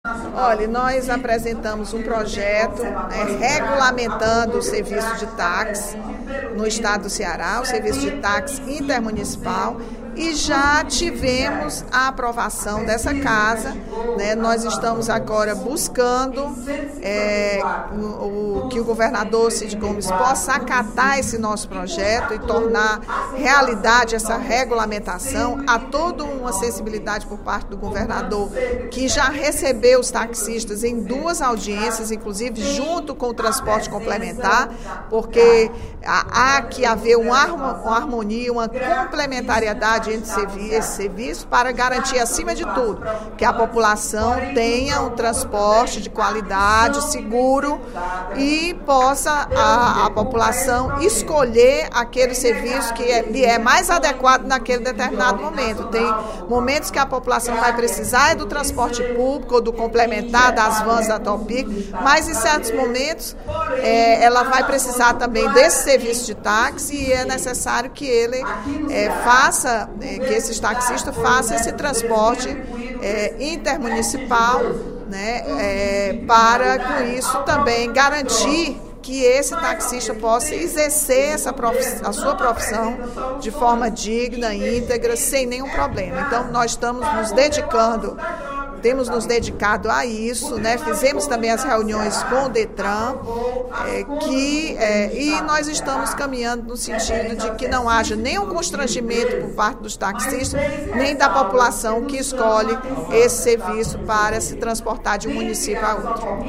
Durante o primeiro expediente da sessão plenária  da Assembleia Legislativa desta quarta-feira (25/06), a deputada Rachel Marques (PT) apelou para que o projeto de indicação sobre a regulamentação do transporte intermunicipal por taxistas no Estado seja acatado pelo o governador Cid Gomes.